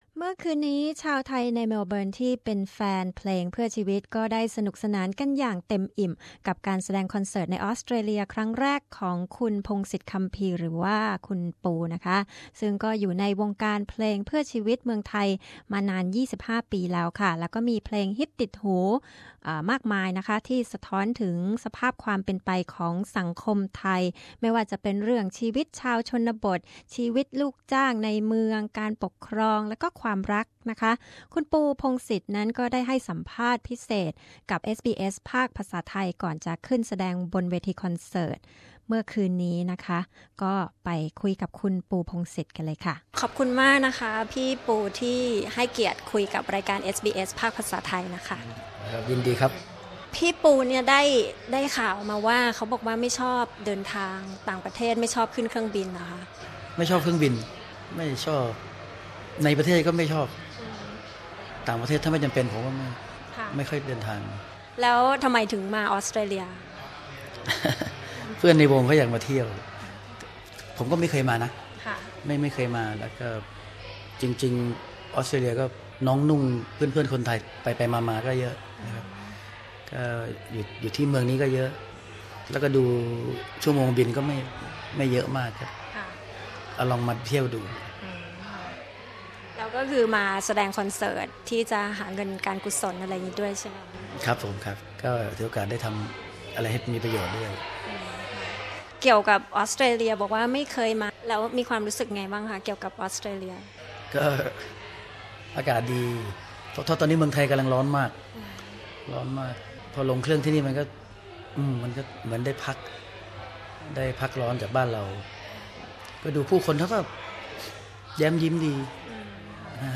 A Chat with Folk Rock Legend Pongsit Kampee